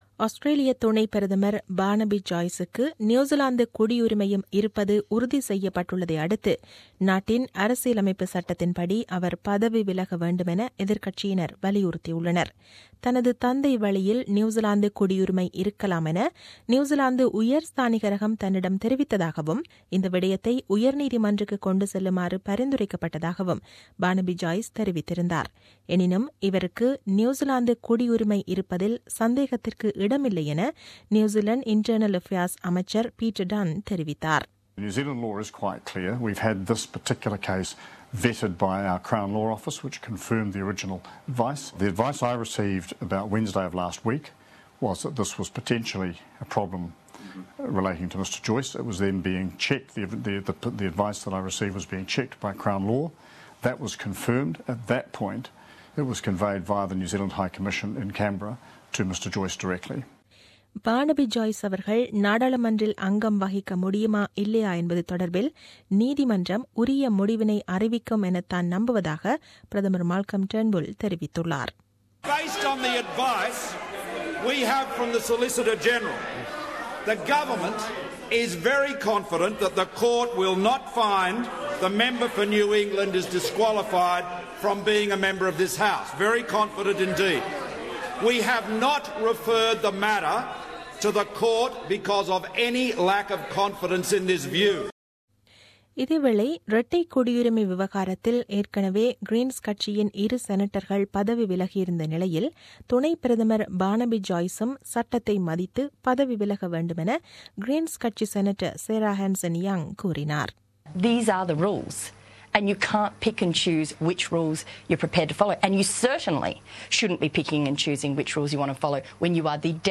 The news bulletin aired on 14 Aug 2017 at 8pm.